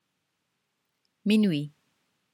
The listening will help you with the pronunciations.